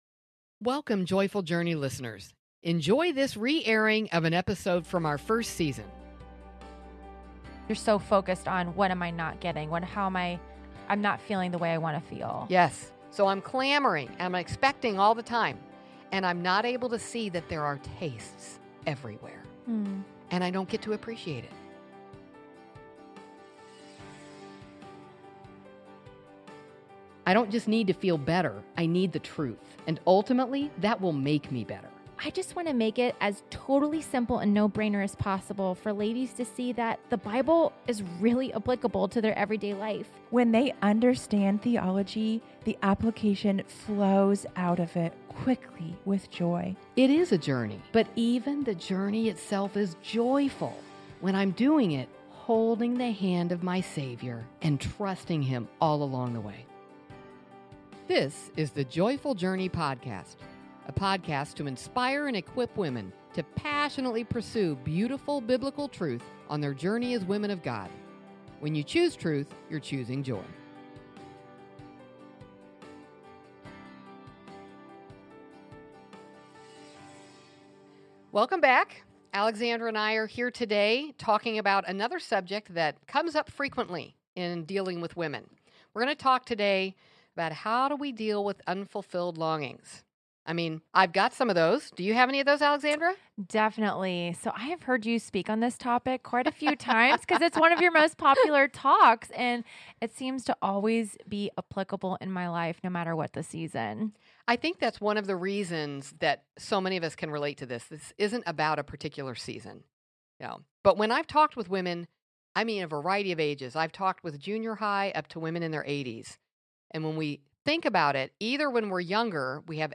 an inspiring conversation about the deep longings within every heart—and how a biblical lens reveals glimpses of God’s goodness all around us. Discover hope in the waiting as you recognize the blessings hidden in everyday moments.